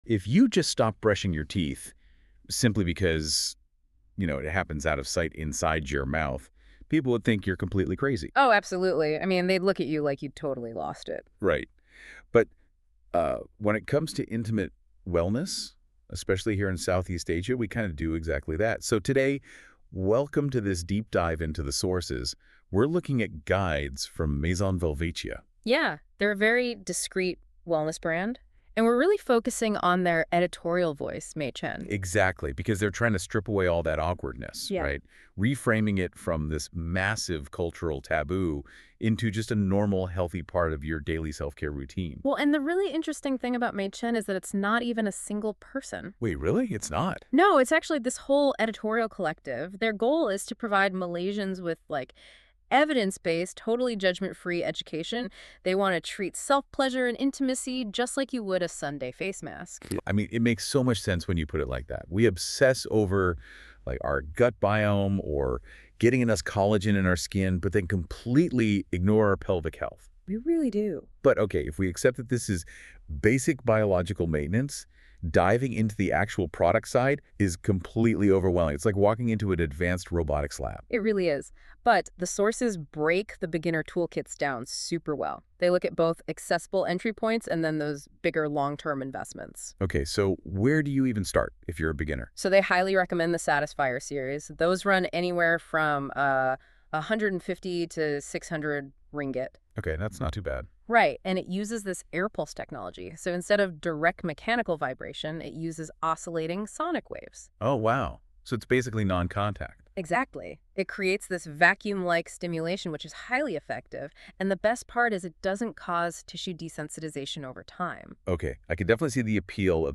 🎧 Listen · Editorial Audio Brief
A short conversation between our editorial team on body-safe basics, discreet shopping in Malaysia, and the thinking behind every Maison Velvetia guide.